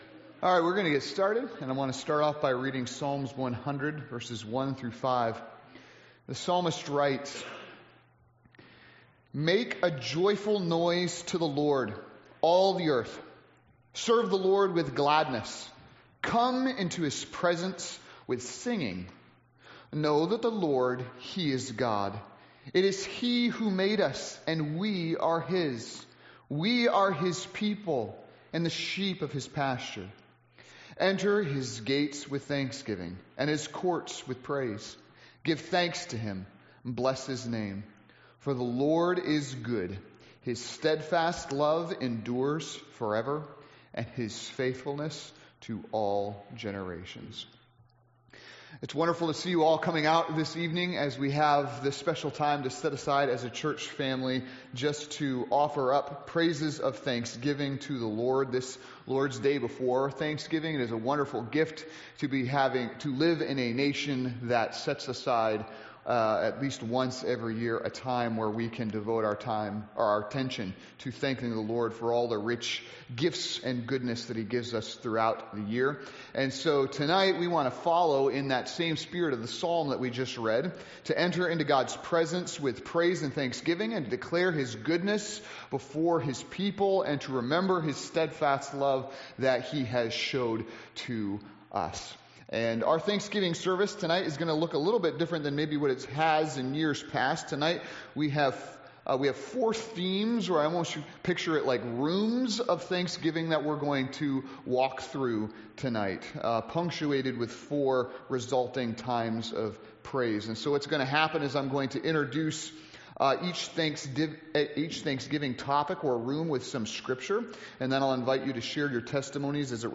Thanksgiving Testimony Service